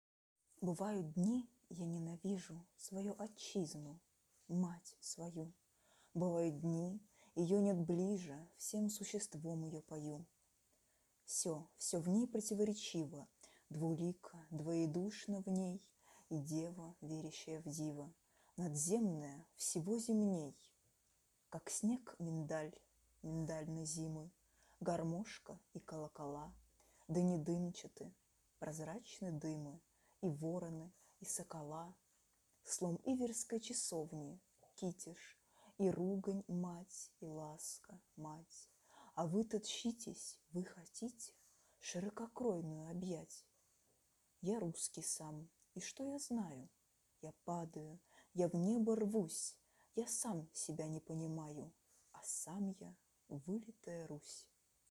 1. «Неизвестен – Читаю стих И.Северянина “Бывают дни я ненавижу…”» /